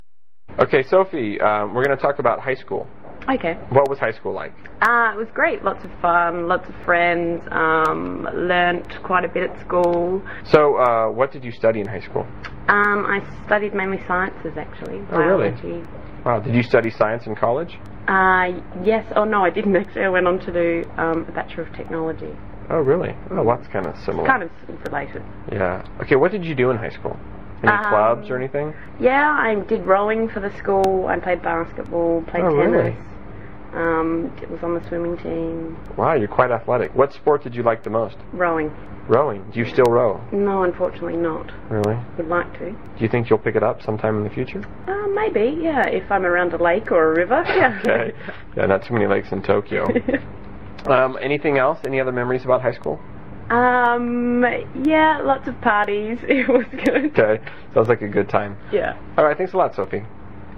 英语高级口语对话正常语速17:高中（MP3）